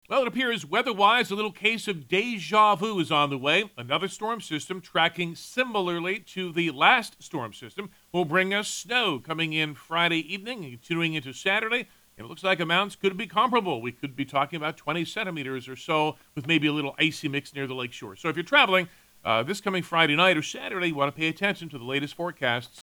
Our meteorologist